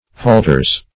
halteres - definition of halteres - synonyms, pronunciation, spelling from Free Dictionary
Search Result for " halteres" : The Collaborative International Dictionary of English v.0.48: Halteres \Hal*te"res\ (h[a^]l*t[=e]"r[=e]z), n. pl.